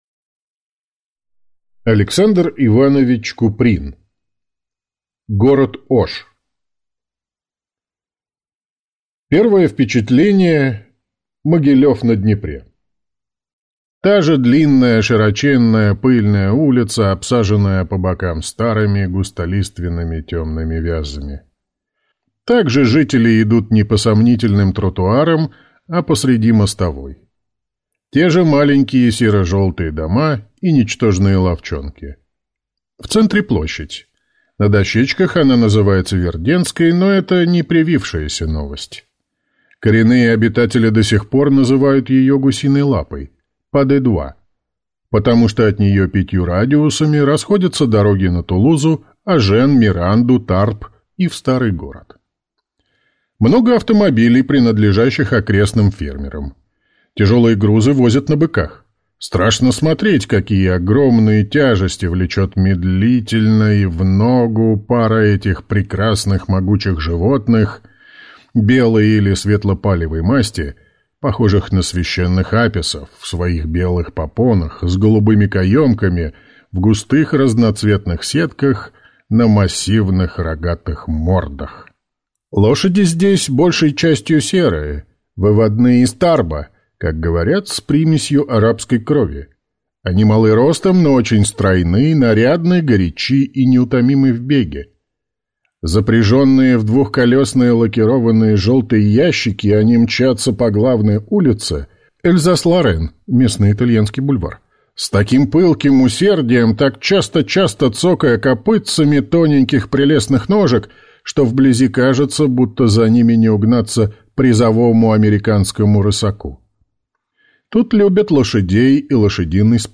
Аудиокнига Город Ош | Библиотека аудиокниг